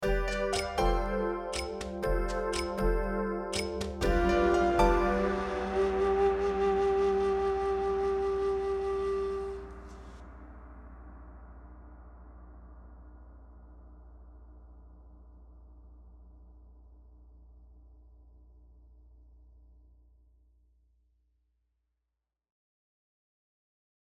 Plays long end of the track